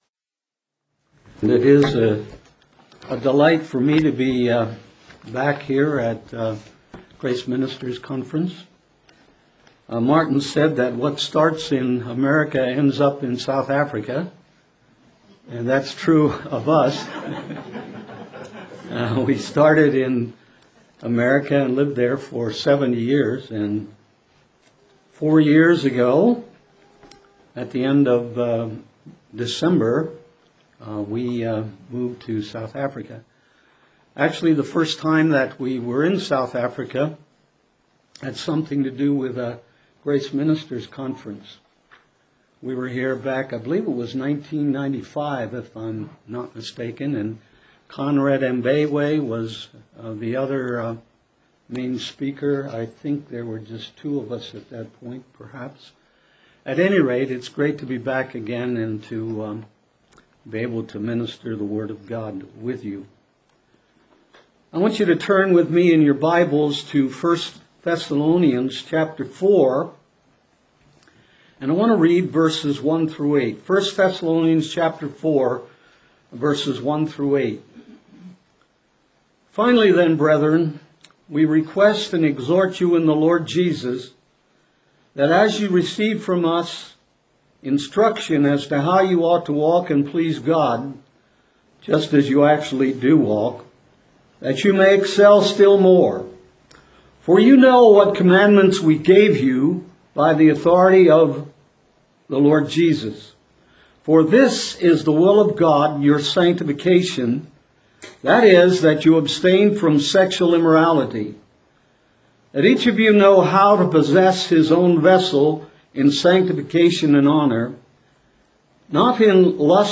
2010 Questions & Answers